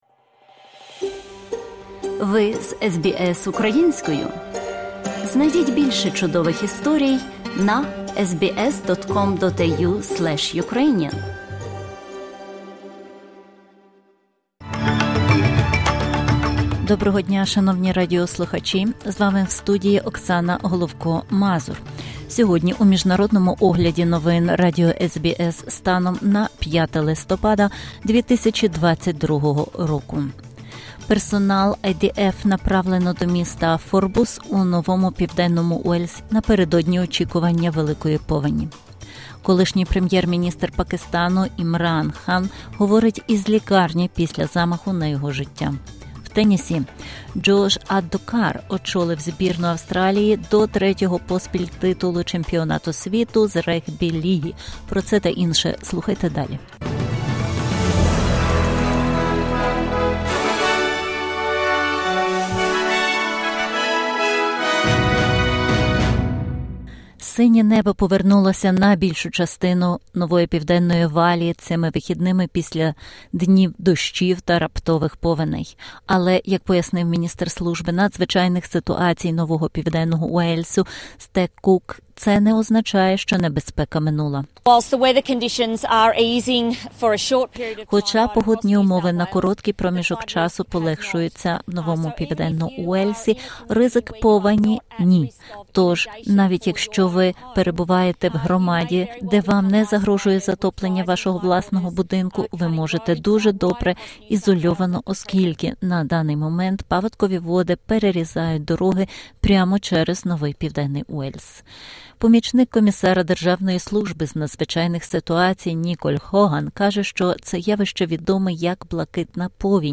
SBS news in Ukrainian - 5/11/2022